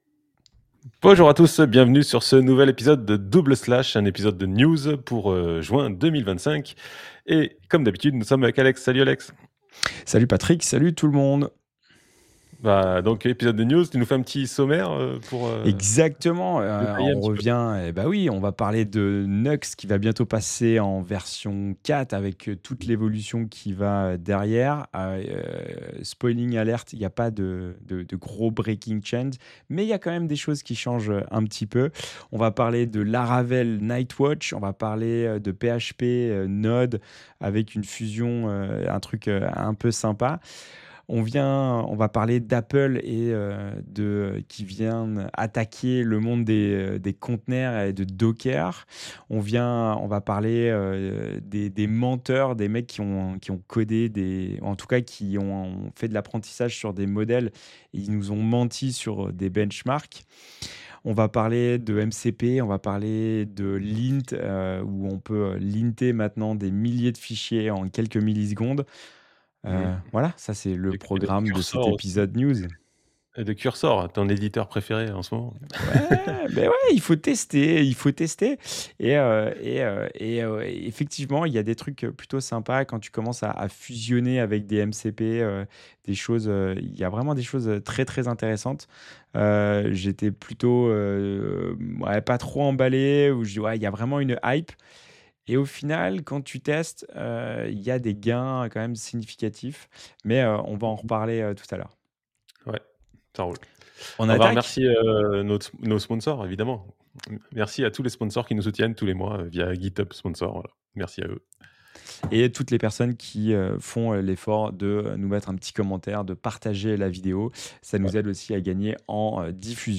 Double Slash, un podcast avec 2 animateurs qui se retrouvent pour discuter des outils et des techniques pour le développement moderne de site web et d’application web. Retrouvez-nous régulièrement pour parler de sujets variés tels que la JAMStack, l’accessibilité, l’écoconception, React JS, Vue JS et des retours d’expériences sur des implémentations.